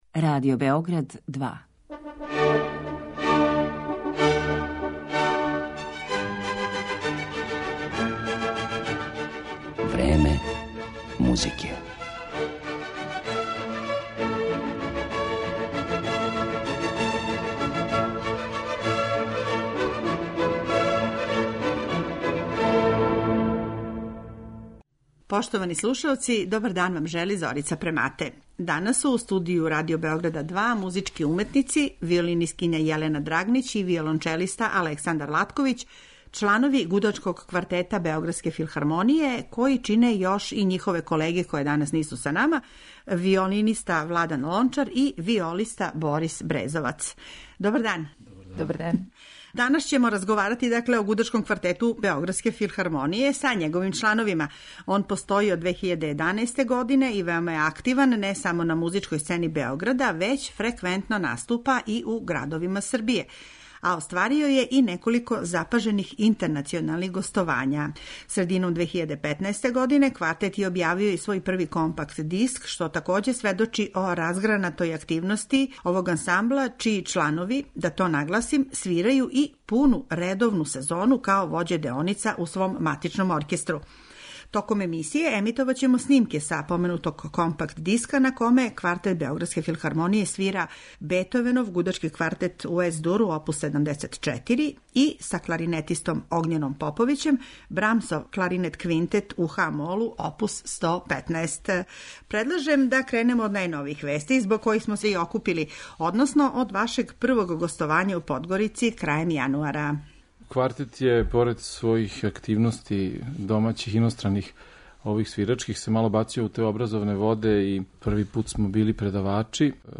Такође, биће речи и о професионалним плановима, а биће емитовани снимци са компакт-диска који је овај квартет објавио прошле године.